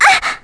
Viska-Vox_Damage_01.wav